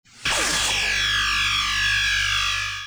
emp.wav